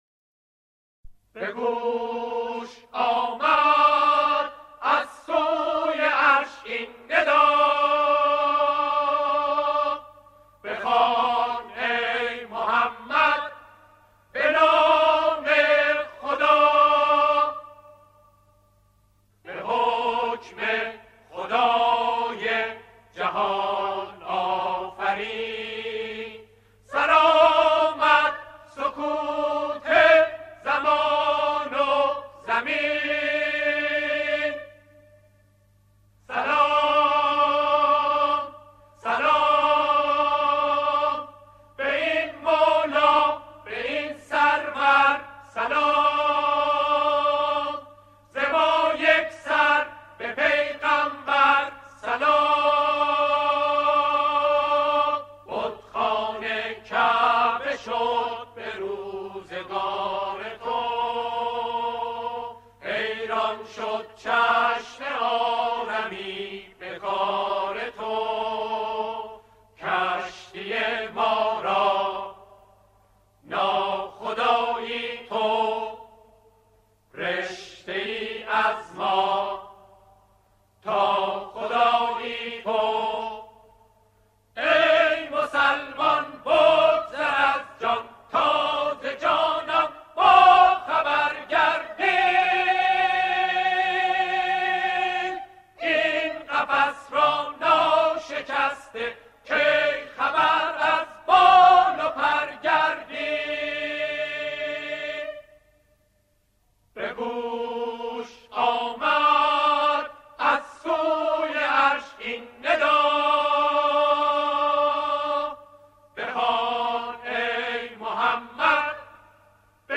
سرود نوستالژی
به صورت آکاپلا